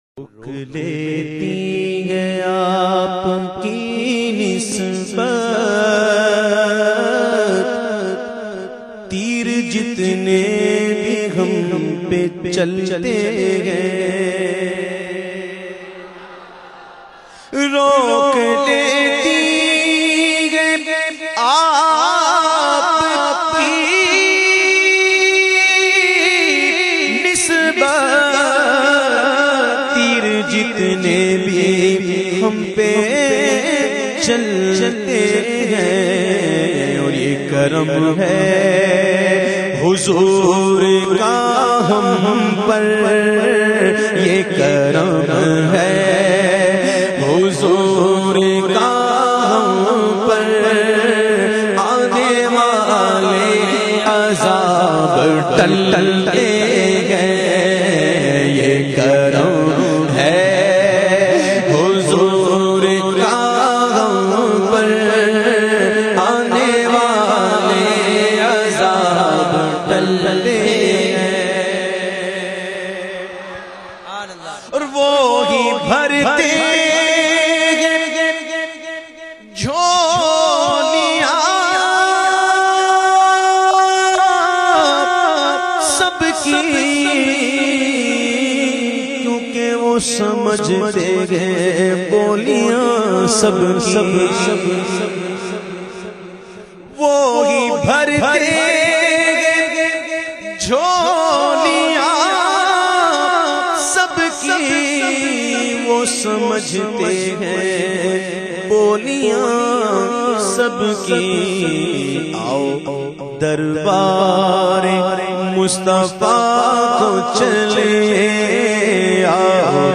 in best audio quality
naat sharif